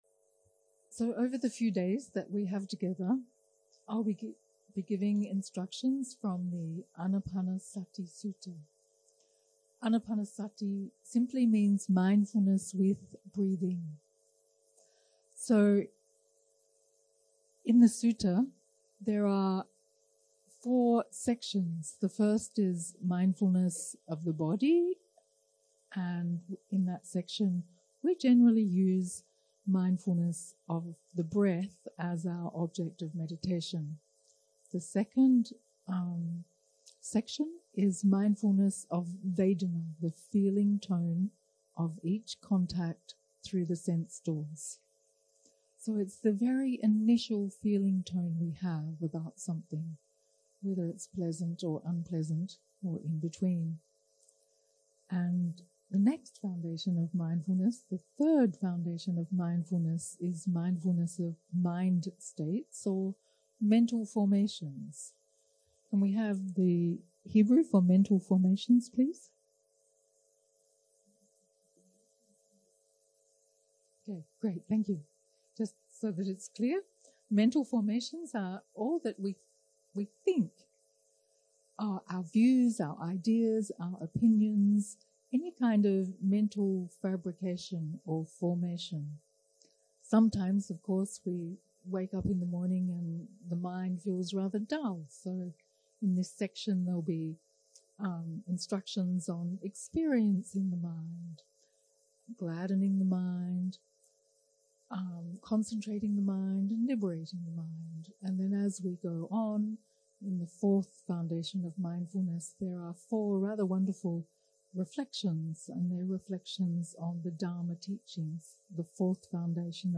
יום 1 - הקלטה 1 - ערב - הנחיות למדיטציה - אנאפאנהסטי סוטה קטגוריה א (גוף ונשימה) 0:00 0:00 סוג ההקלטה: Dharma type: Guided meditation שפת ההקלטה: Dharma talk language: English